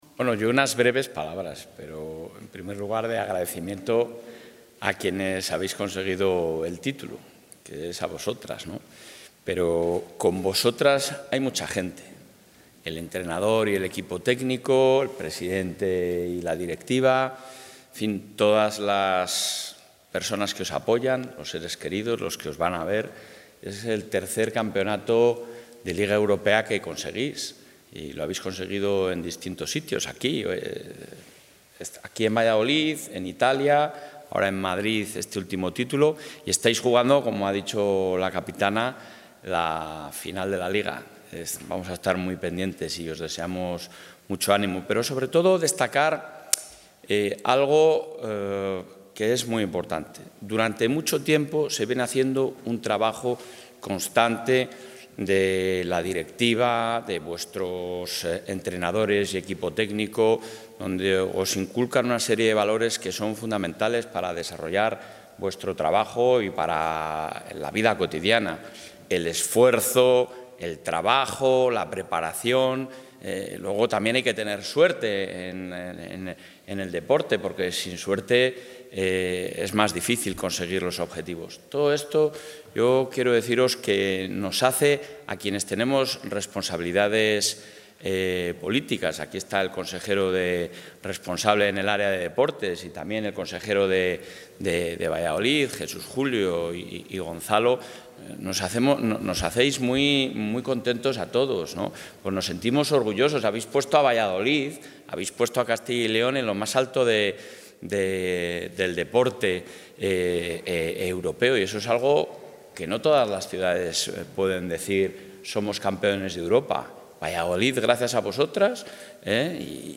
En la recepción que ha tenido lugar en la Sala de Mapas de la sede de la Presidencia, el presidente de la Junta de Castilla y León,...
Intervención del presidente.